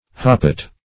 Search Result for " hoppet" : The Collaborative International Dictionary of English v.0.48: Hoppet \Hop"pet\, n. 1. A hand basket; also, a dish used by miners for measuring ore.